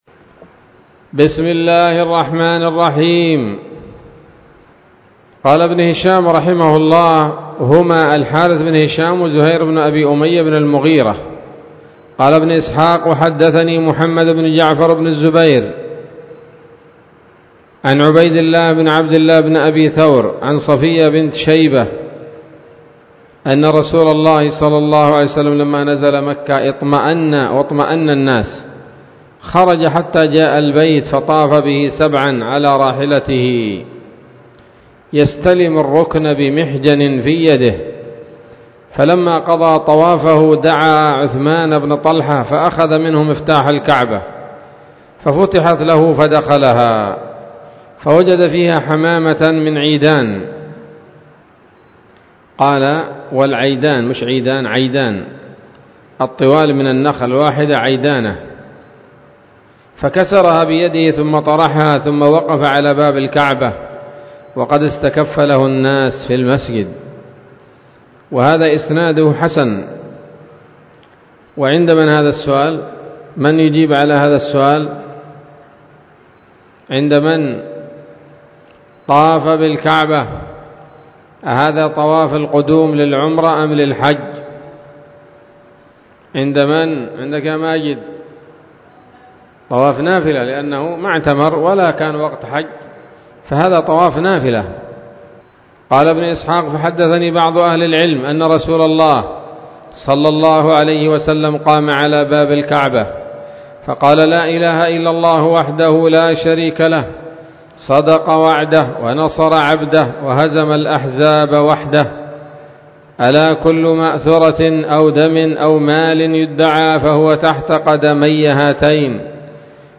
الدرس الثاني والستون بعد المائتين من التعليق على كتاب السيرة النبوية لابن هشام